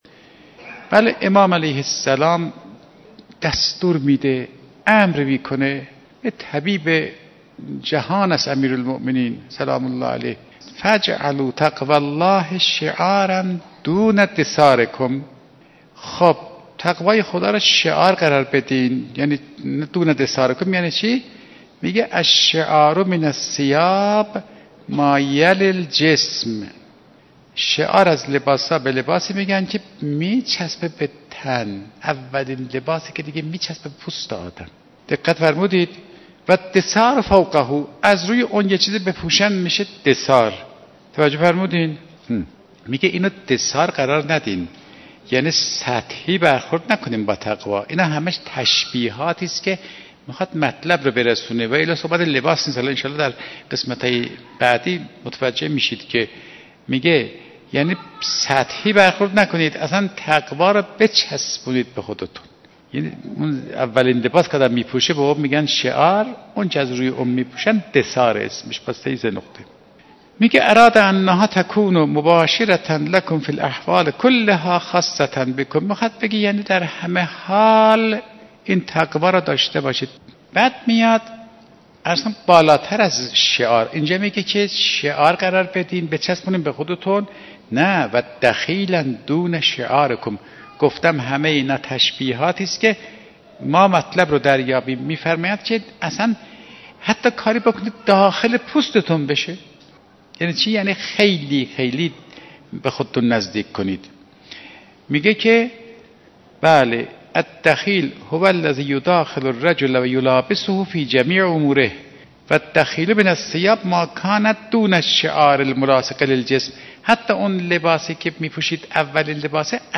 1 آخرین مطالب موسیقی سخنرانی
صوت سخنرانی